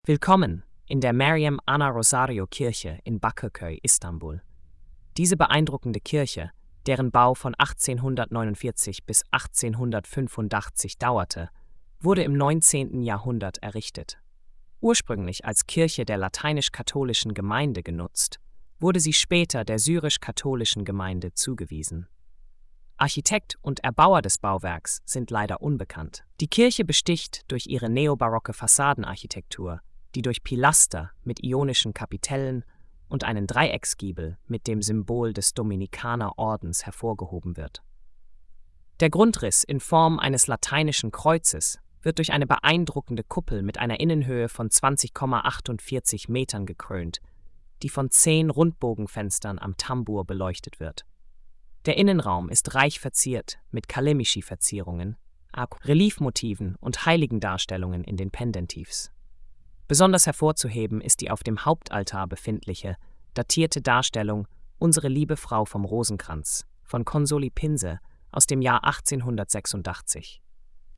HÖRFASSUNG DES INHALTS: